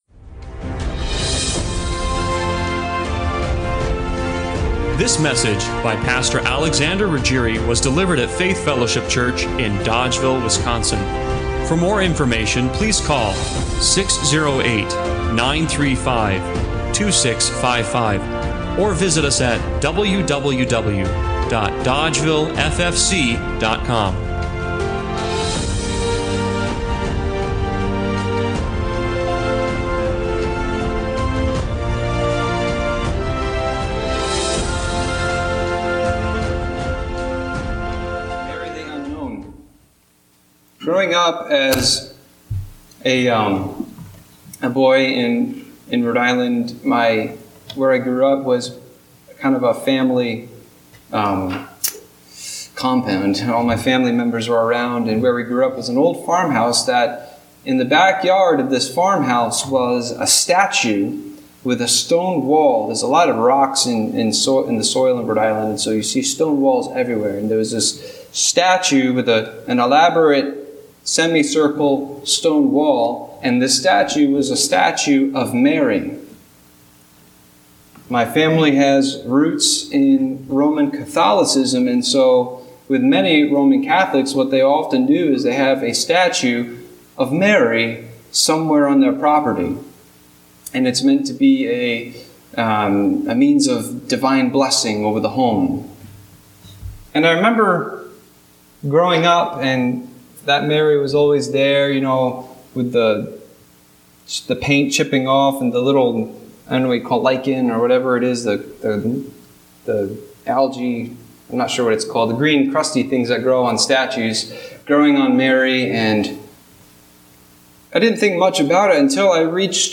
Luke 1:26-38 Service Type: Sunday Morning Worship What does it mean to be a courier?